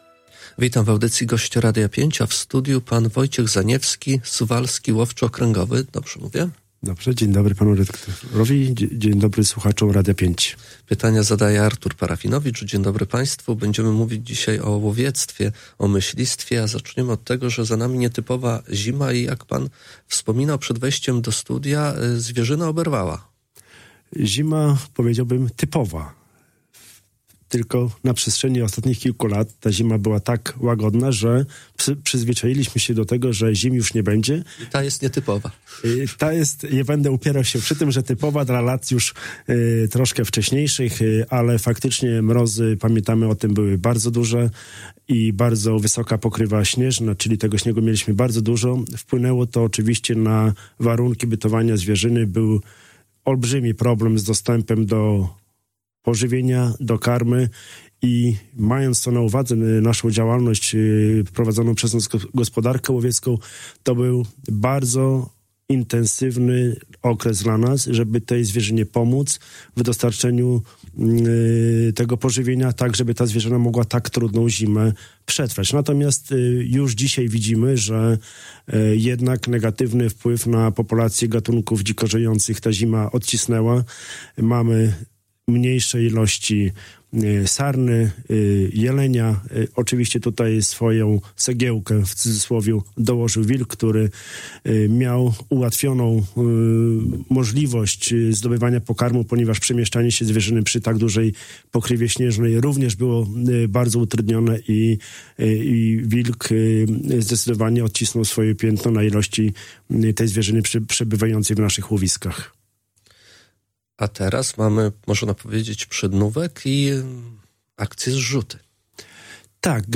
Cała rozmowa z Łowczym, poniżej: